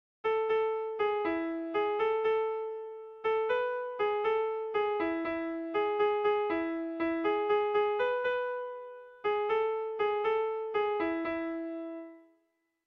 Utzan utzan isilik - Bertso melodies - BDB.
Irrizkoa
ABD